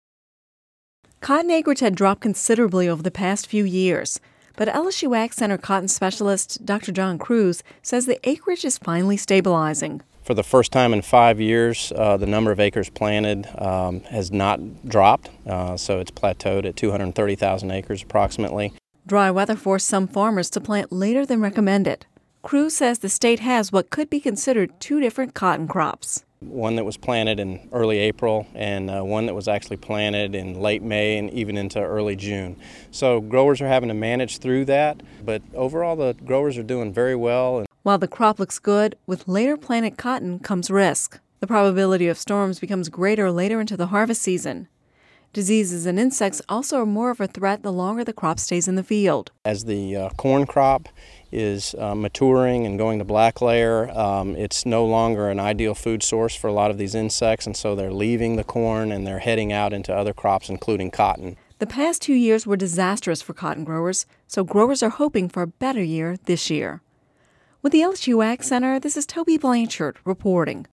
(Radio News 08/02/10) Cotton acreage had dropped considerably over the past few years